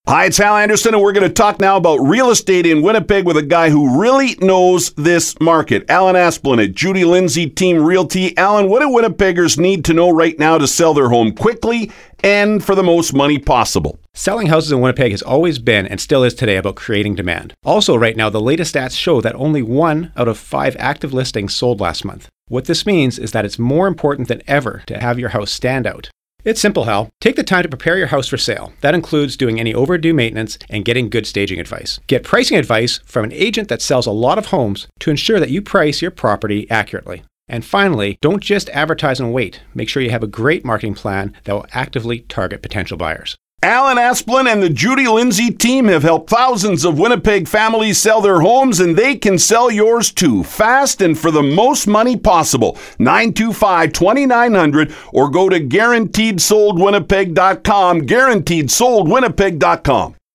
Interview 1